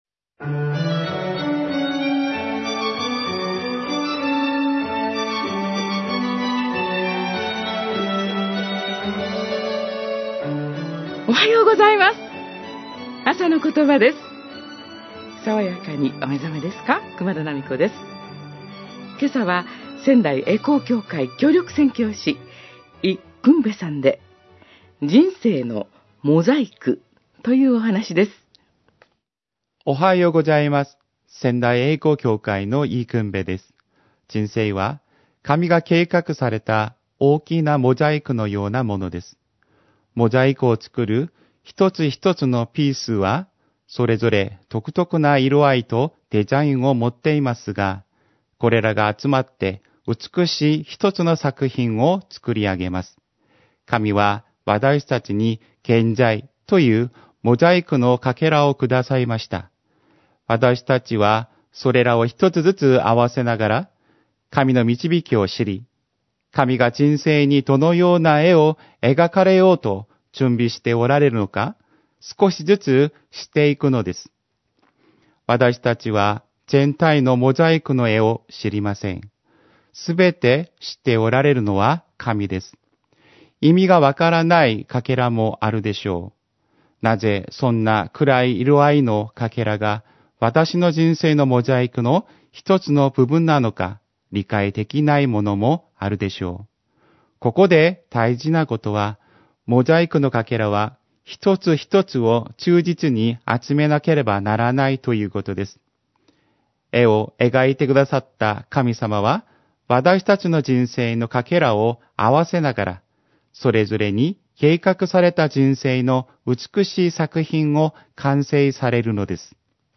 メッセージ： 人生のモザイク